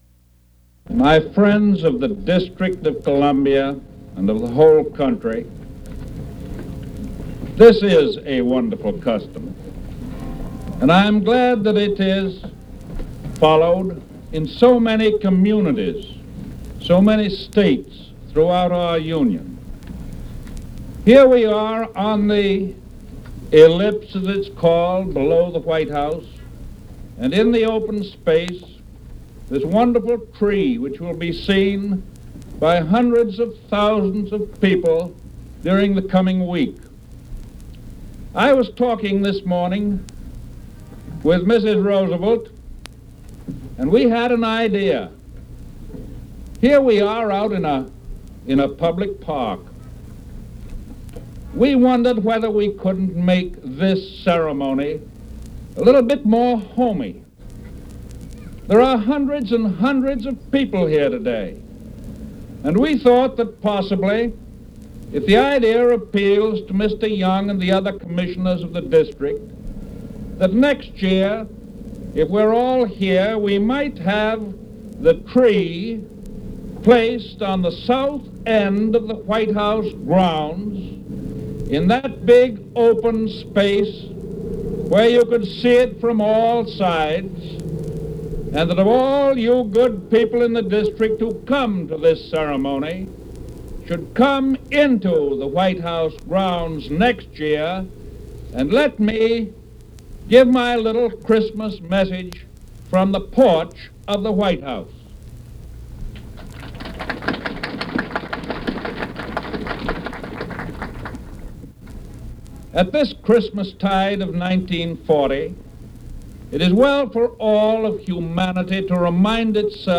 U.S. President Franklin D. Roosevelt delivers remarks at community Christmas tree lighting
Subjects Christmas trees Christmas Politics and government United States Material Type Sound recordings Language English Extent 00:08:44 Venue Note Broadcast 1940 December 24.